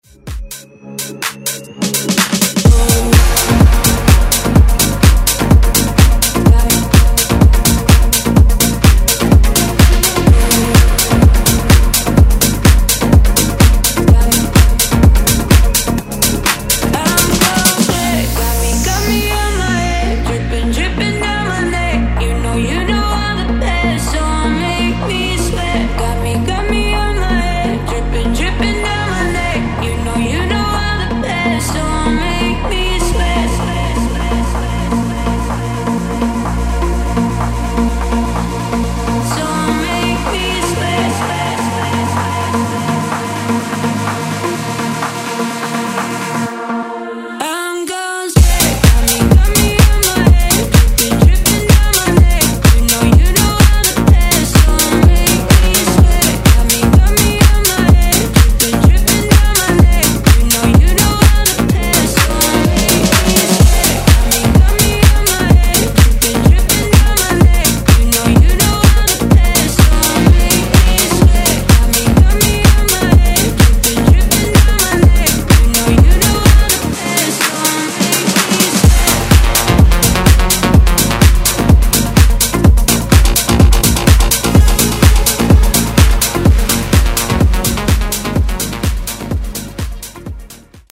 Genre: MOOMBAHTON
Dirty BPM: 93 Time